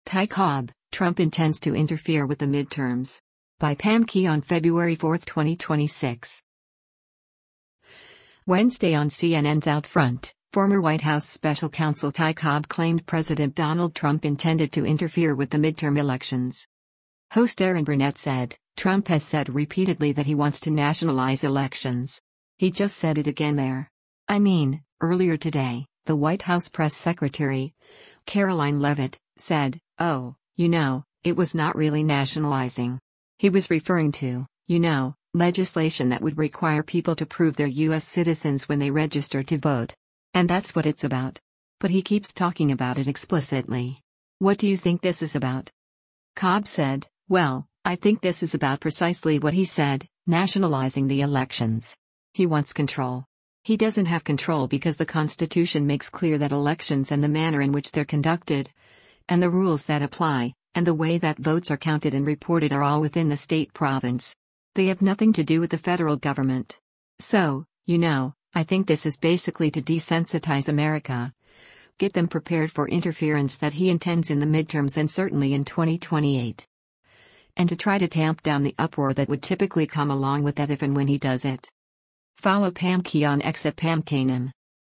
Wednesday on CNN’s “OutFront,” former White House special counsel Ty Cobb claimed President Donald Trump intended to interfere with the midterm elections.